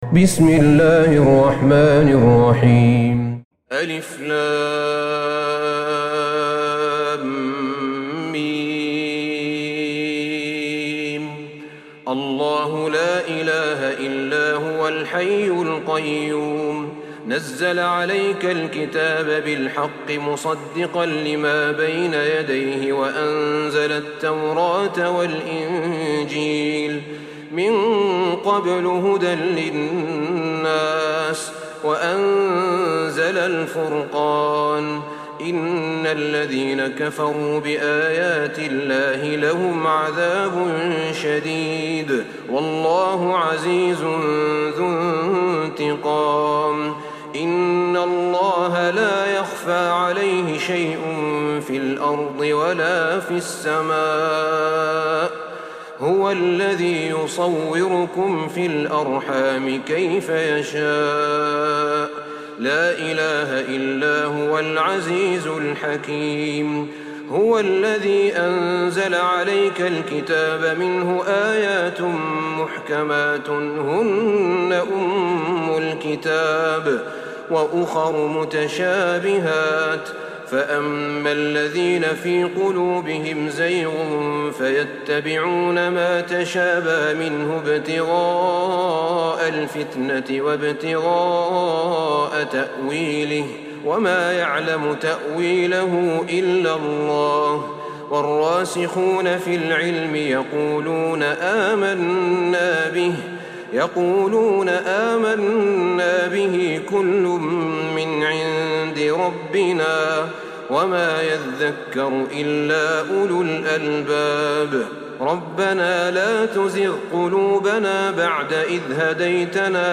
سورة آل عمران Surat Al'Imran > مصحف الشيخ أحمد بن طالب بن حميد من الحرم النبوي > المصحف - تلاوات الحرمين